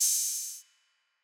Southside Open Hatz (2).wav